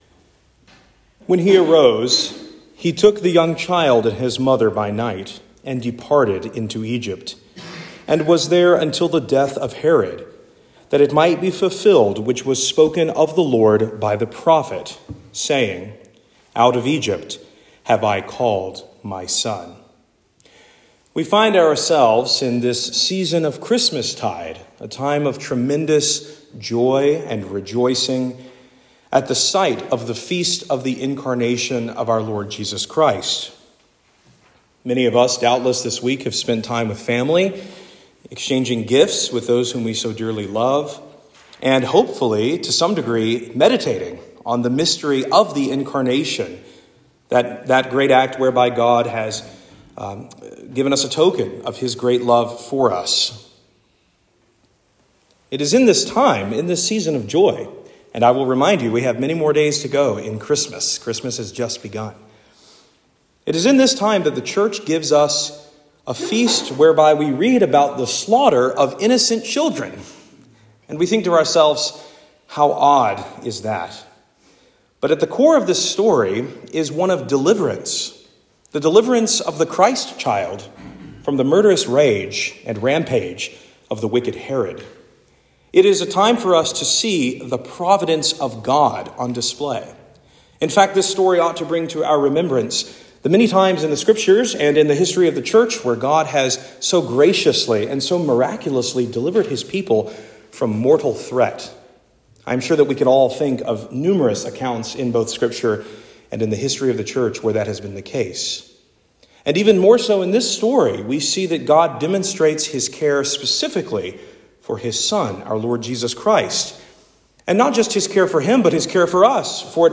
Sermon for Holy Innocents